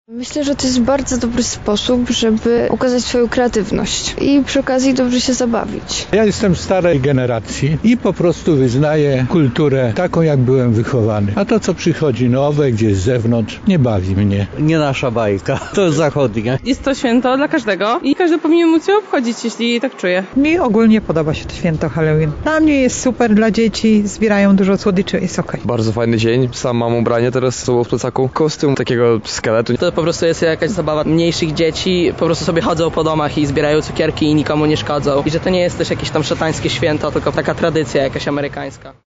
Zapytaliśmy lublinian, co oni sądzą na temat tego święta:
SONDA